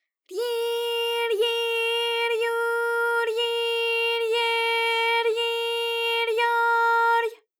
ALYS-DB-001-JPN - First Japanese UTAU vocal library of ALYS.
ryi_ryi_ryu_ryi_rye_ryi_ryo_ry.wav